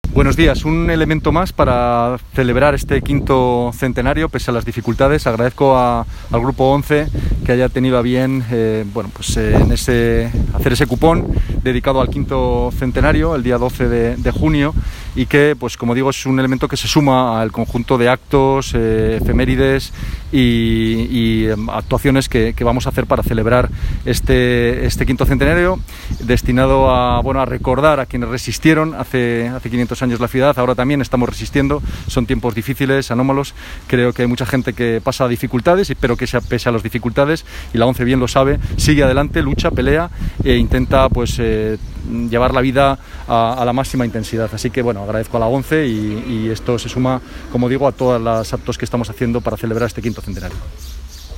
Descarga de documentos Audio de la rueda de prensa Descargar